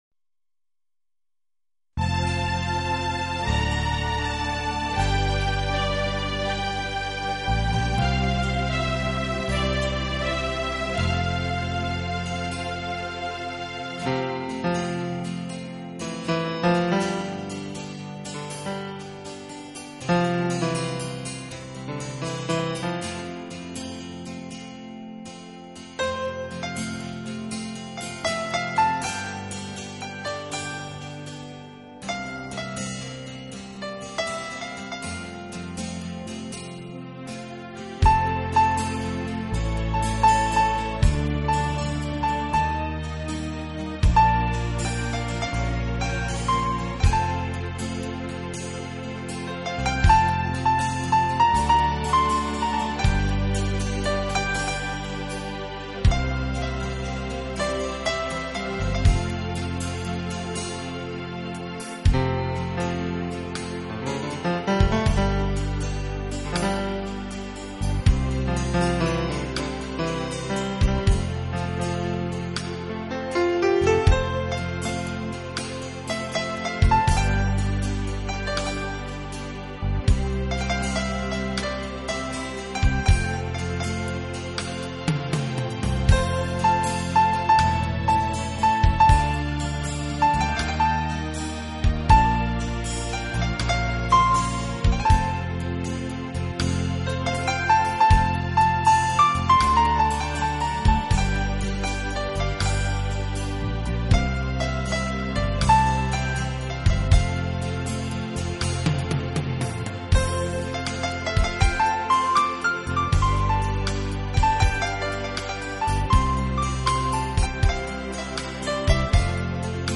【浪漫钢琴】
这是一套非常经典的老曲目经过改编用钢琴重新演绎的系列专辑。
钢琴演奏版，更能烘托出复古情怀，欧美钢琴大师深具质感的演奏功力，弹指
本套CD全部钢琴演奏，